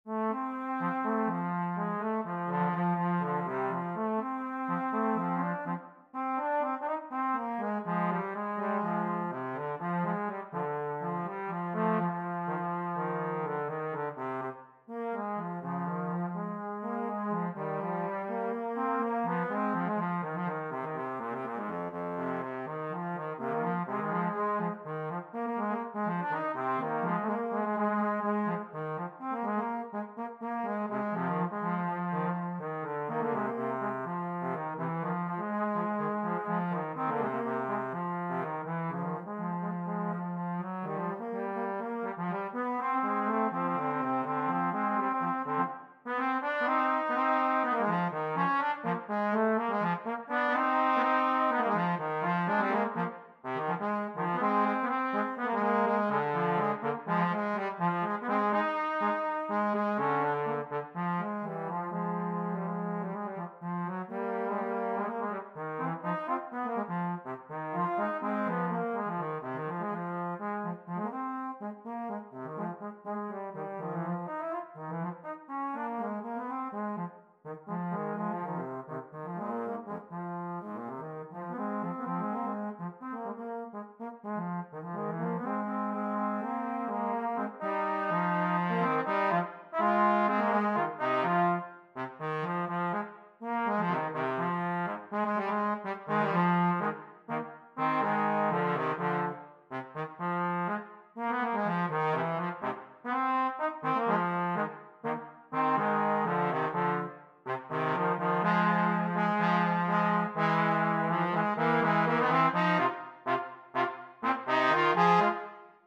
Gattung: Für 2 Posaunen
Besetzung: Instrumentalnoten für Posaune